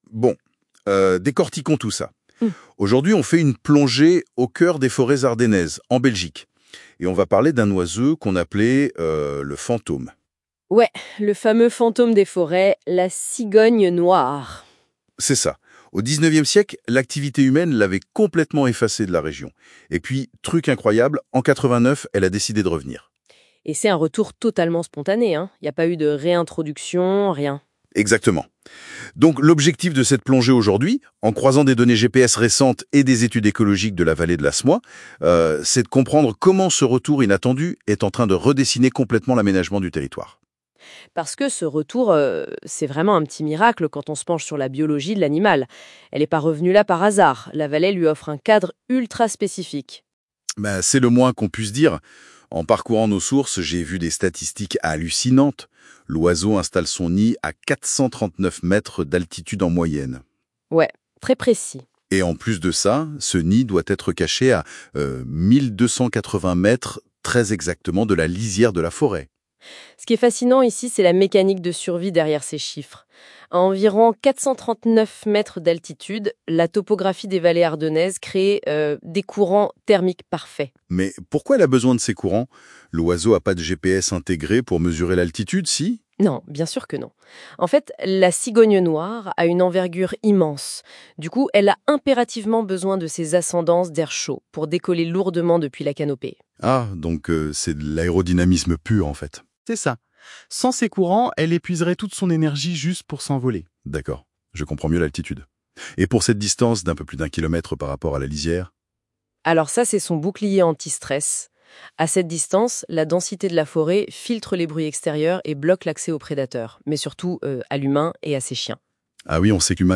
Narrated audio story: history of the return, biology, habitat and protection of the black stork (Ciconia nigra) in the Semois Valley and the Walloon Natura 2000 network.